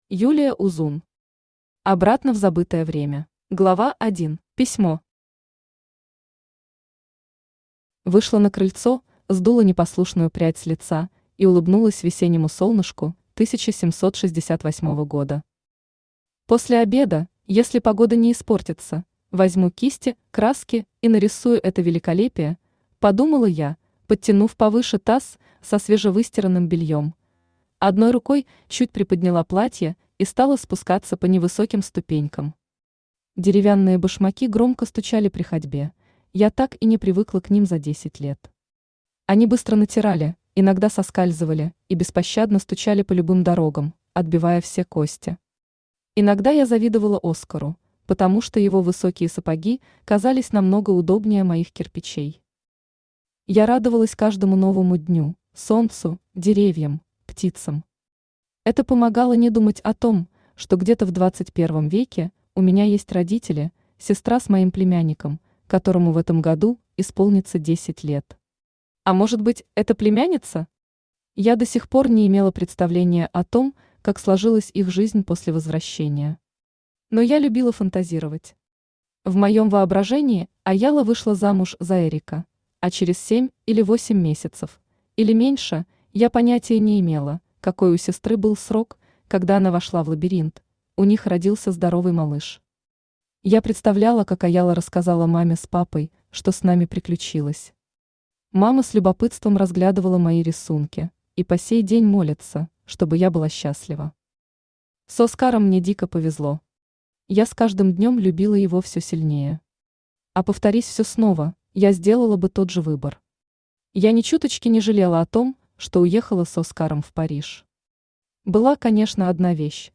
Aудиокнига Обратно в забытое время Автор Юлия Узун Читает аудиокнигу Авточтец ЛитРес.